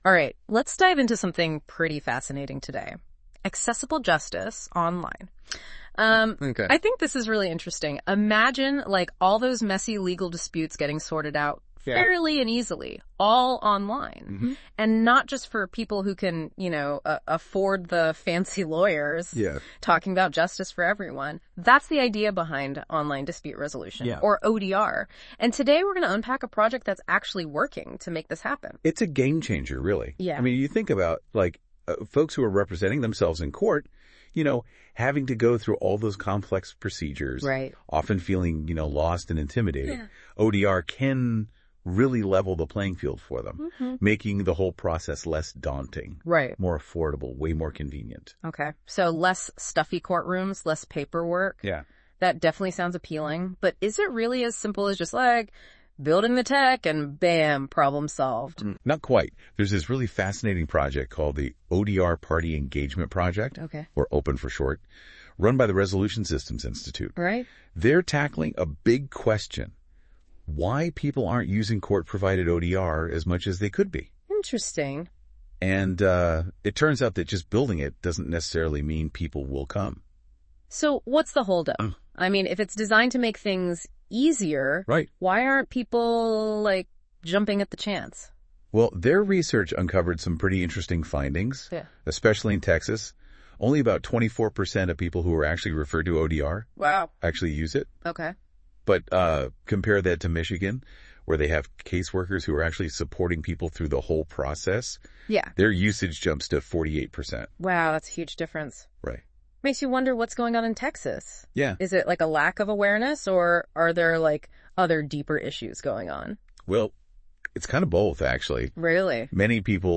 This tool transforms written content into dynamic audio discussions, where AI hosts summarize key insights, link related topics and create an engaging conversation.
Click here to listen to the AI-generated podcast summary of this article